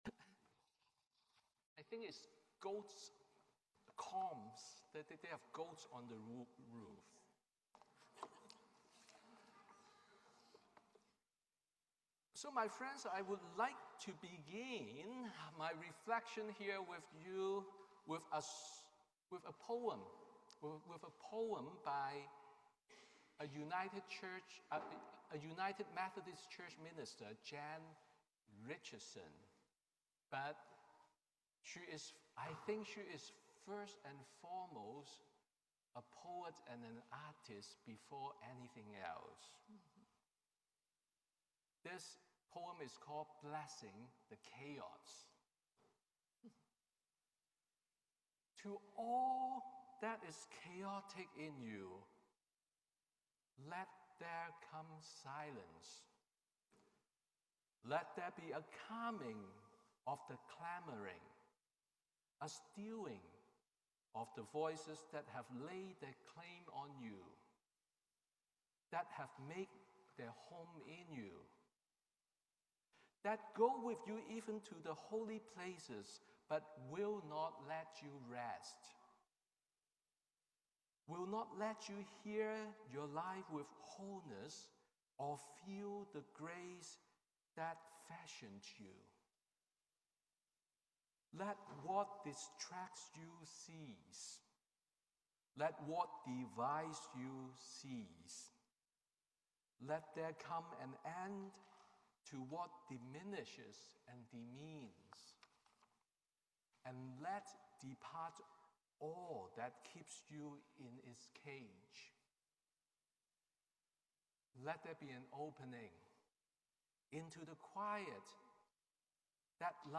Sermon on the First Sunday of Advent